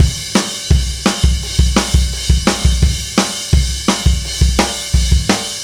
Indie Pop Beat 05.wav